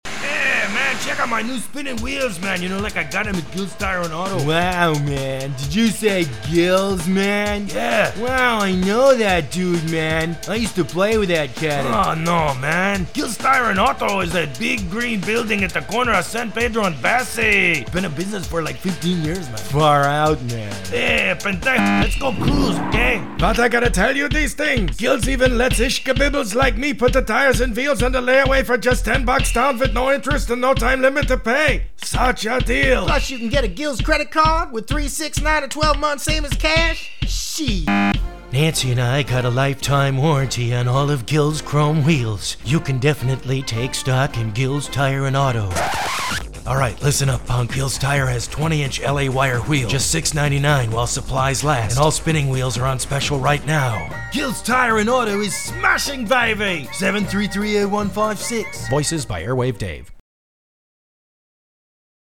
Voiceover Samples